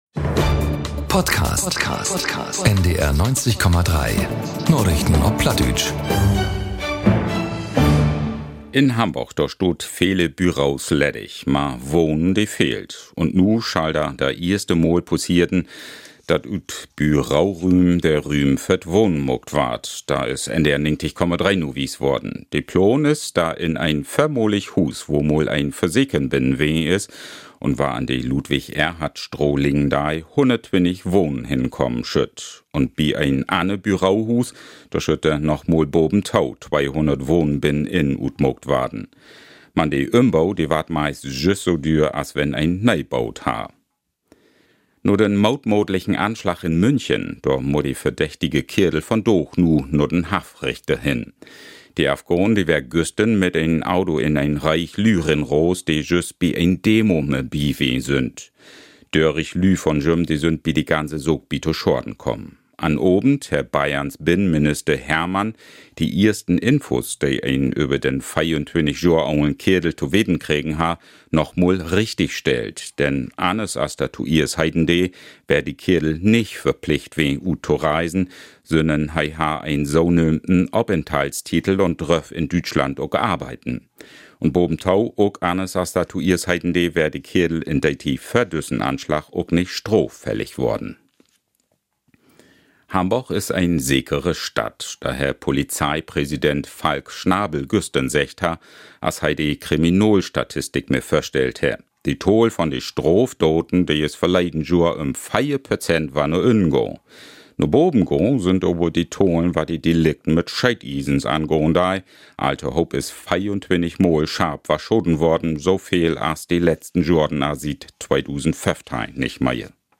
Ut Hamborg un de wiede Welt - vun maandaags bet sünnavends: Die aktuellen Nachrichten auf Plattdeutsch bei NDR 90,3.